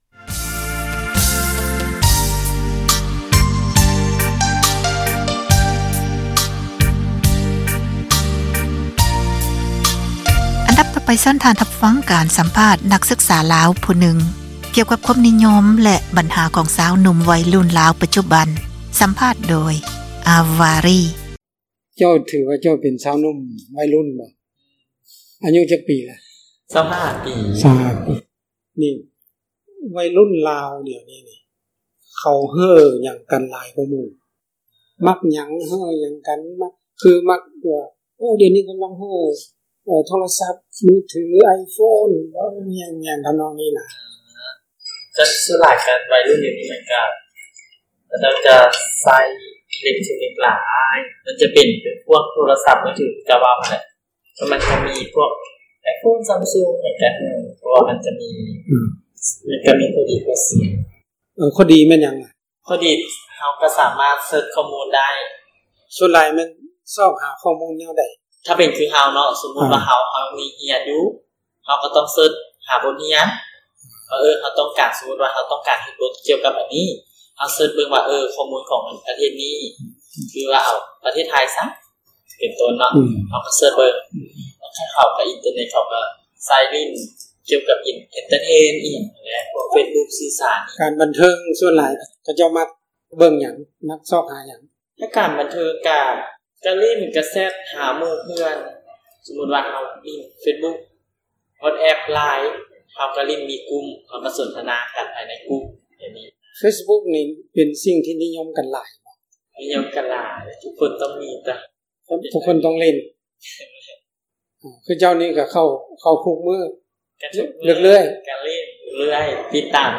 ສັມພາດນັກສຶກສາລາວ